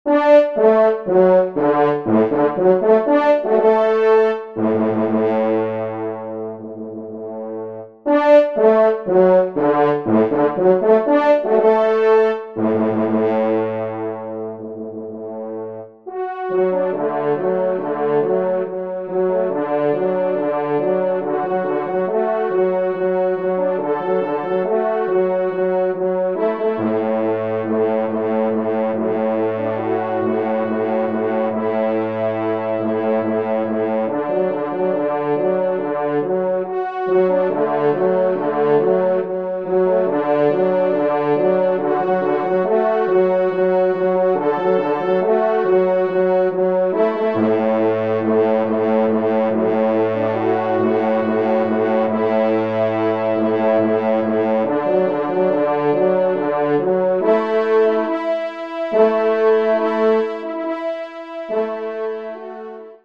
3ème Trompe